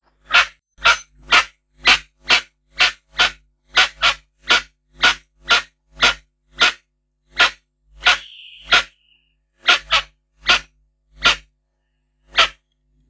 Penélope obscura obscura - Pava de monte
pavademonte.wav